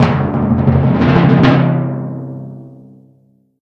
Song: Sfx - Tournament End Drums